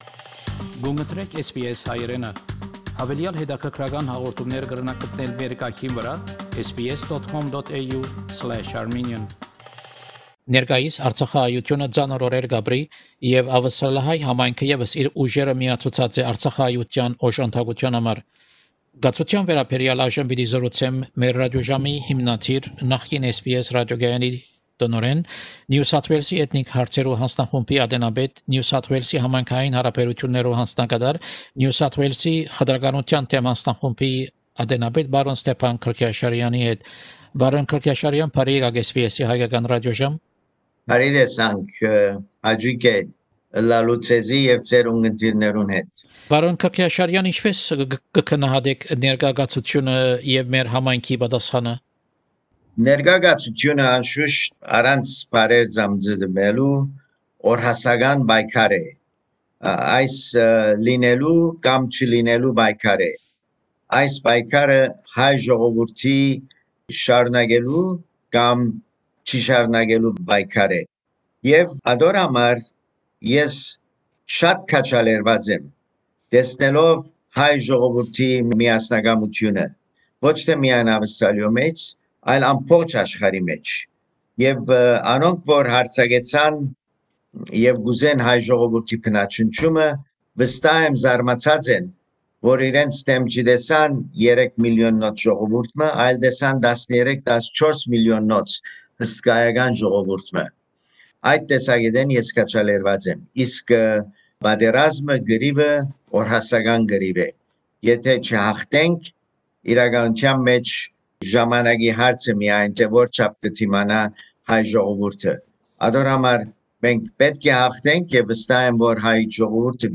Հարցազրոյց մեր ռատիօժամի հիմնադիր, նախկին SBS Ռատիօկայանի տնօրէն, NSW-ի Էթնիք Հարցերու Յանձնախումբի ատենապետ, NSW-ի Համայնքային Յարաբերութիւններու Յանձնակատար, NSW-ի Խտրականութեան դէմ Յանձնախումբի ատենապետ Պրն Ստեփան Գրքեաշարեանի հետ: